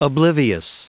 Prononciation du mot oblivious en anglais (fichier audio)
Prononciation du mot : oblivious